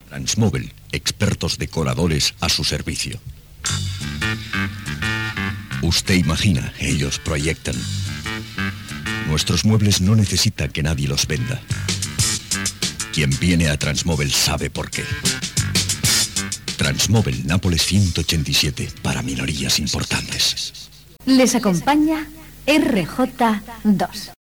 Publicitat i indicatiu de l'emissora Gènere radiofònic Publicitat